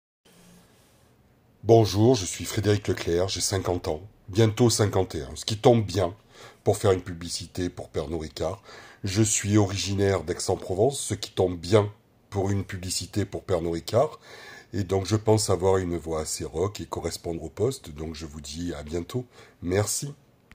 Voix off
Sans Accent